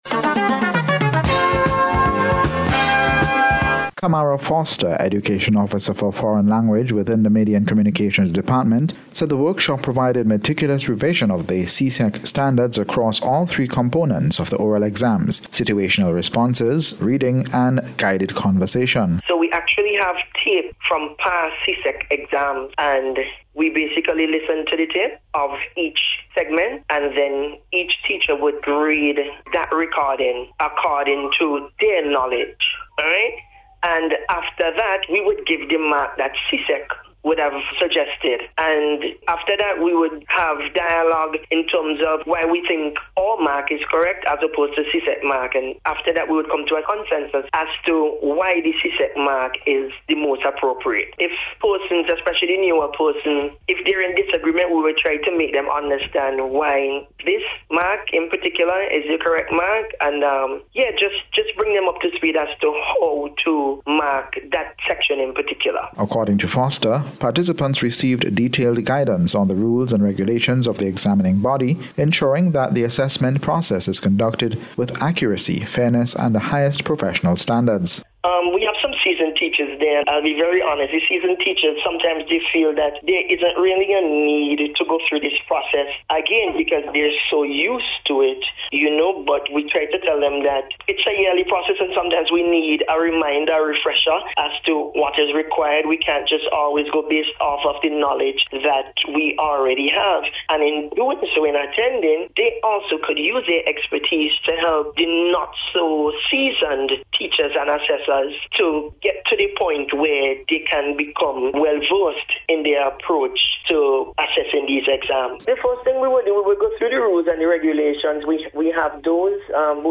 in today’s Special Report.